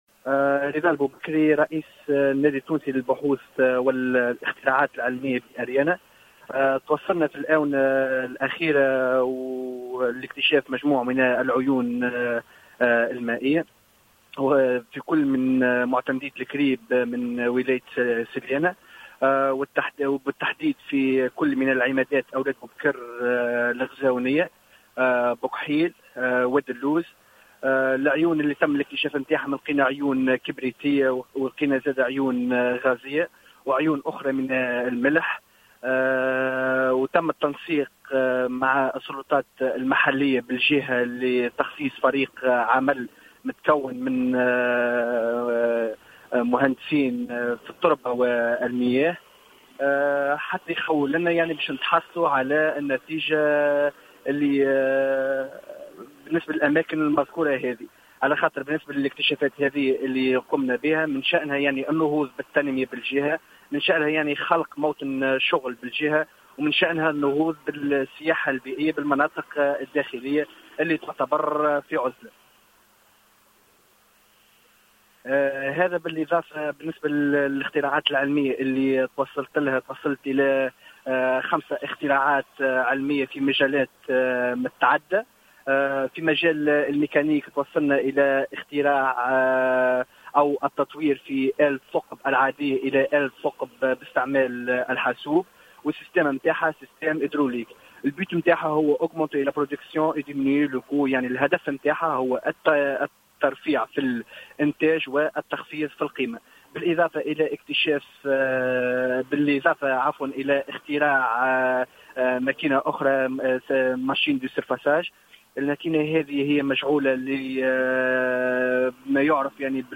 تصريح هاتفي للجوهرة أف أم